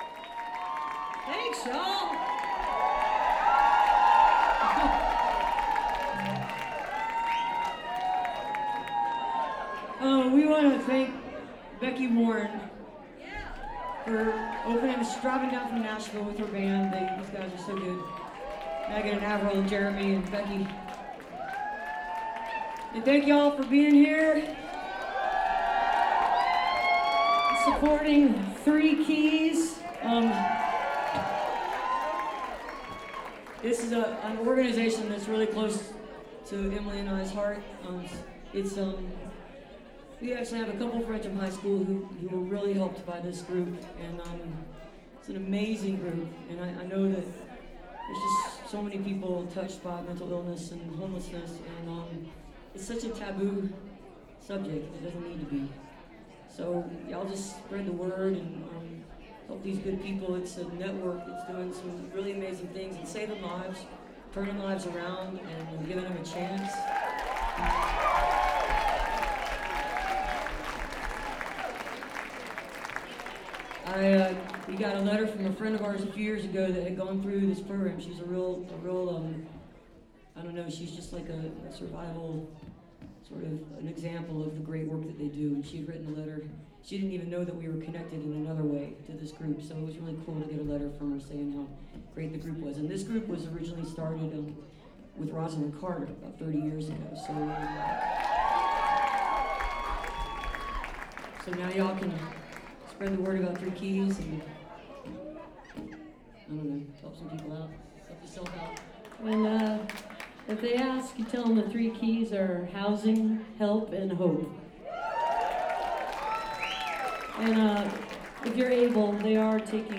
lifeblood: bootlegs: 2017-01-04: terminal west - atlanta, georgia (benefit for project interconnections)
23. banter on 3 key (2:17)